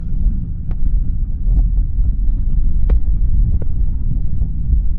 Eerie Wind
Eerie Wind is a free horror sound effect available for download in MP3 format.
046_eerie_wind.mp3